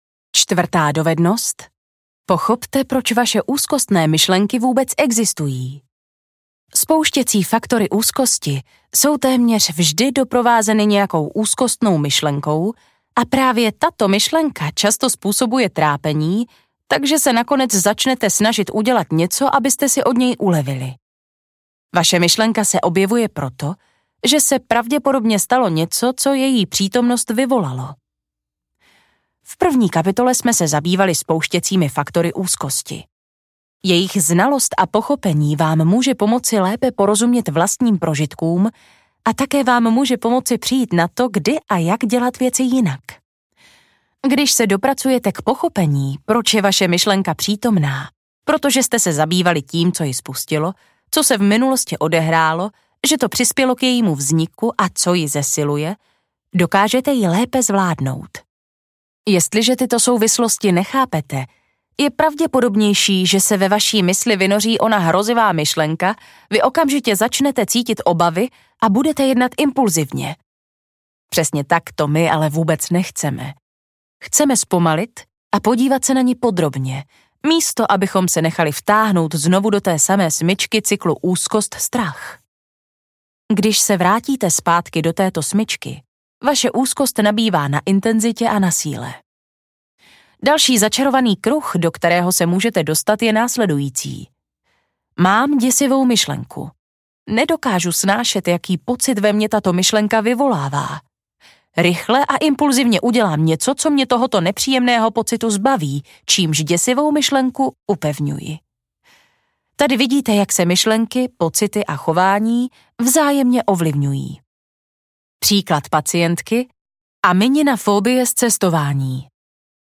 Audio knihaDesetkrát klidnější
Ukázka z knihy